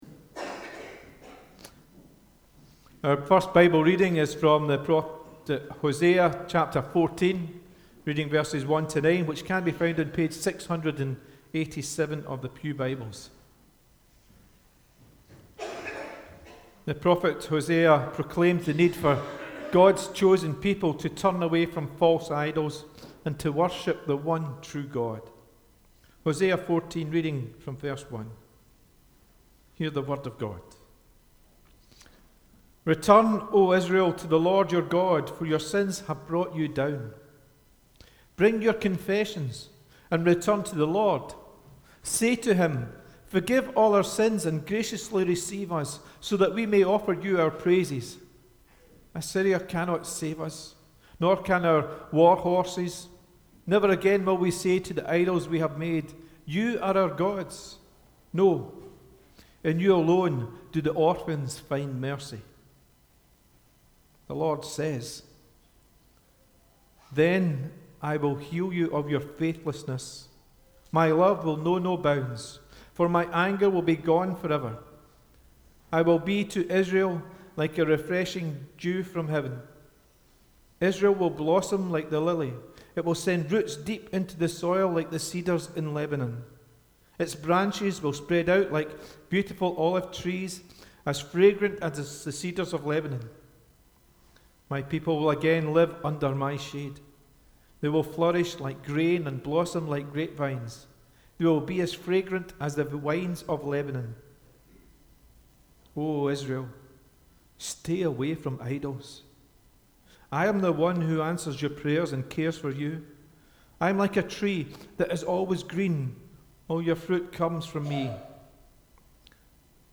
The Readings prior to the Sermon were taken from Hosea 14